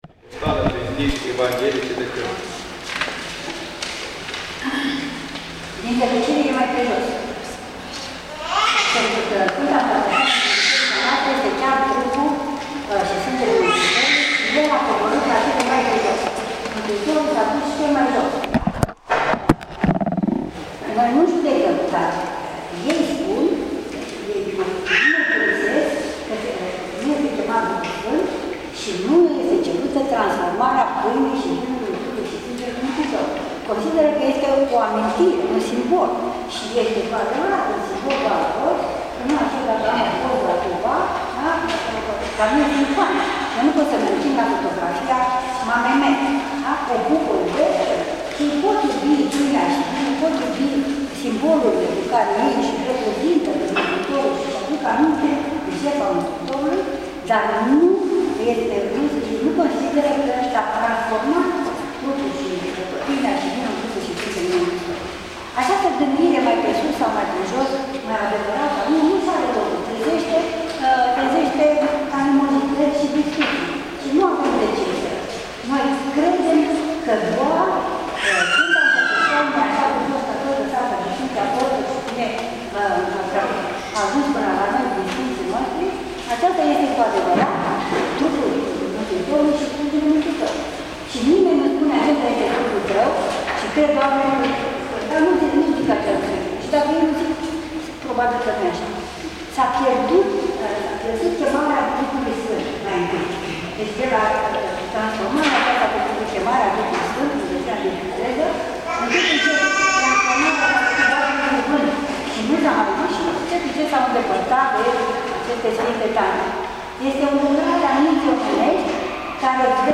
Cuvânt după Sf. Liturghie, Stuttgart, 8 septembrie 2016 - partea a II-a | Centrul de formare şi consiliere Sfinţii Arhangheli Mihail şi Gavriil